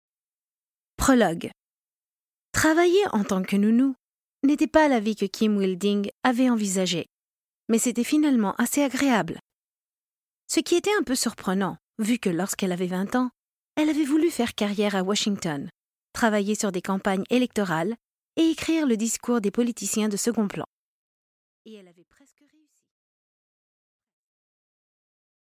Аудиокнига Le mensonge d’un voisin | Библиотека аудиокниг
Прослушать и бесплатно скачать фрагмент аудиокниги